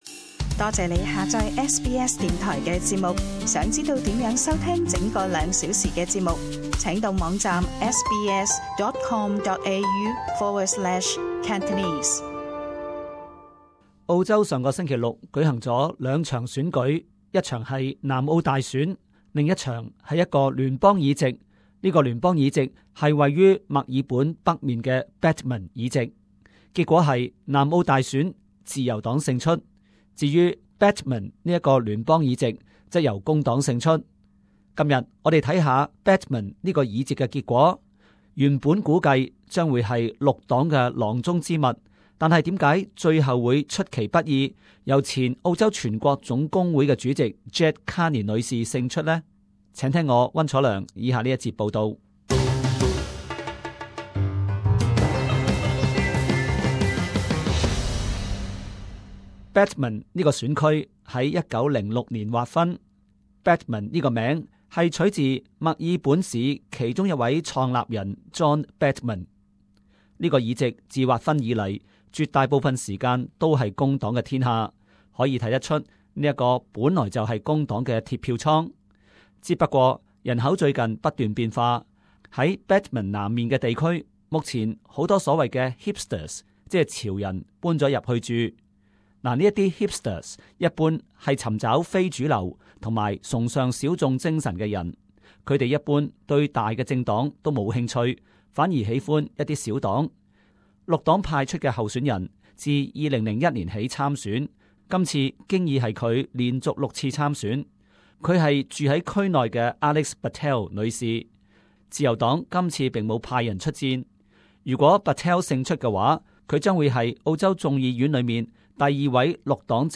【时事报导】 工党如何摘下 Batman 议席？